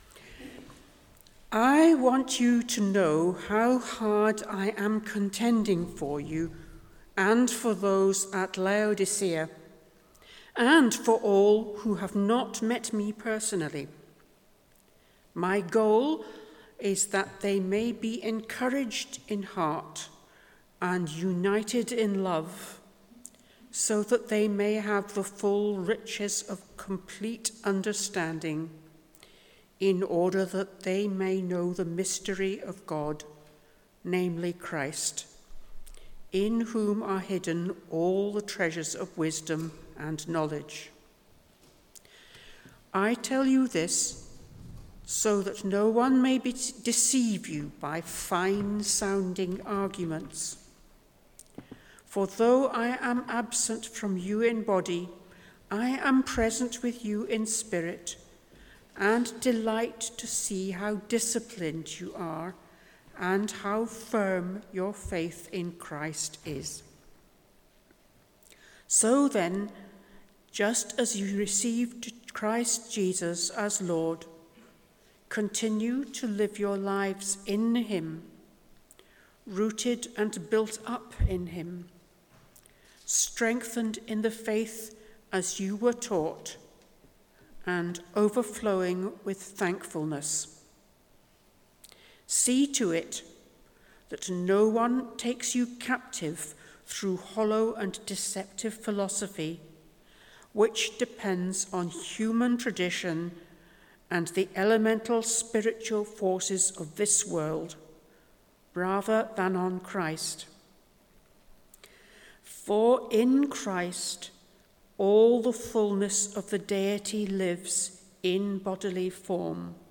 Passage: Colossians 2:1-10 Service Type: Sunday Morning « Your identity is a reflection of the God we worhip Who is He that I may believe?